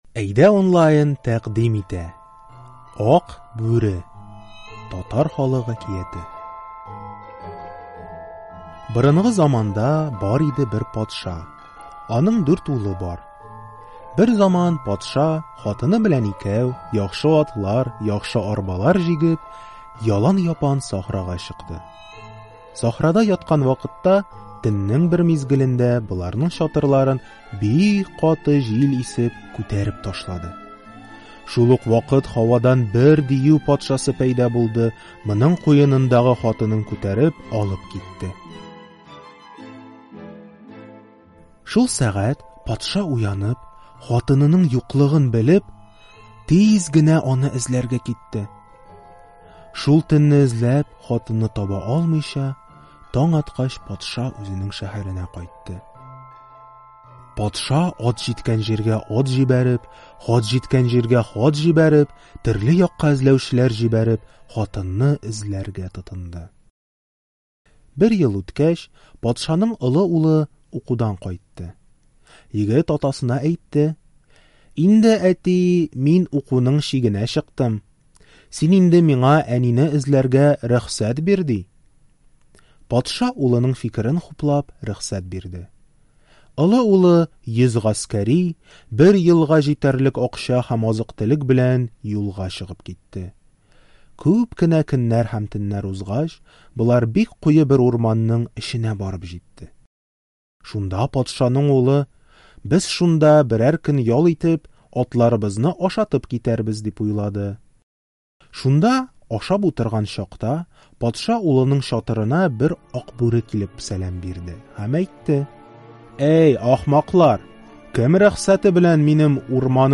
Читаем интересную татарскую сказку "Ак бүре". Текст сказки сокращен для изучающих язык, мы записали к нему аудио, перевели ключевые фразы и слова, подготовили тест по лексике и содержанию.